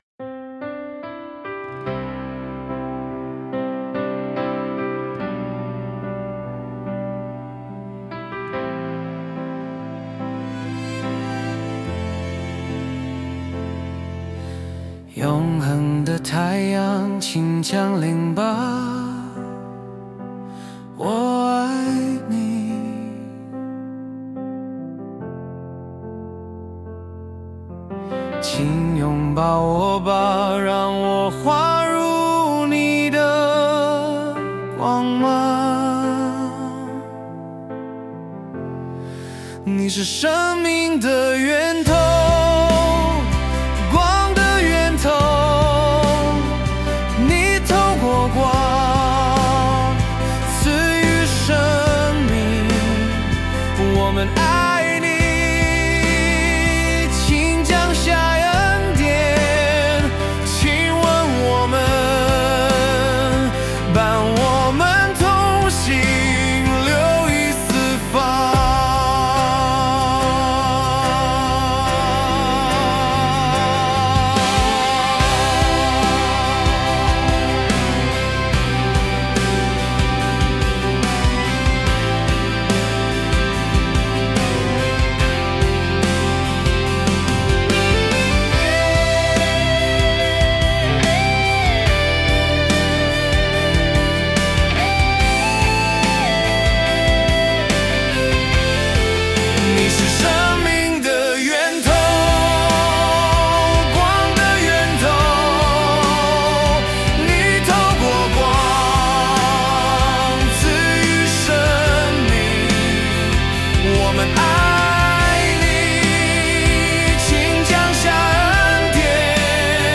我以此为歌词，用AI创建了两首歌曲，我自己颇为喜爱。